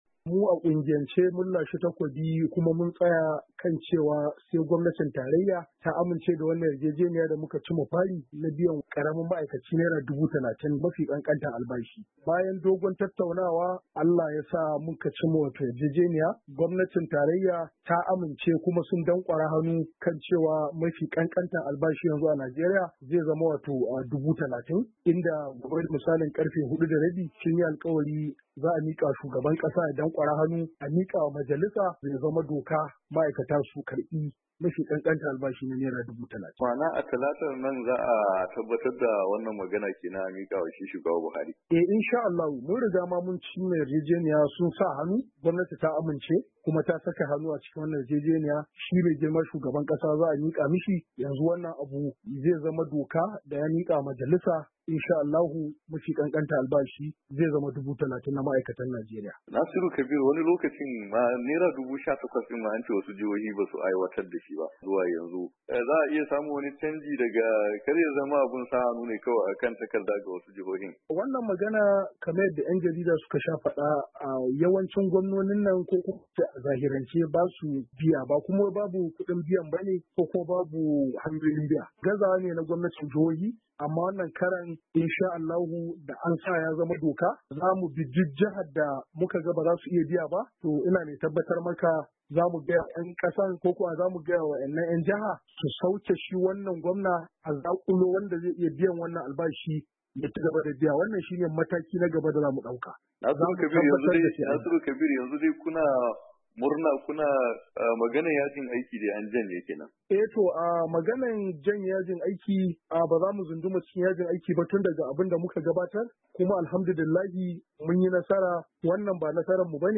ABUJA. HIRA DA KUNGIYAR MA AIKATA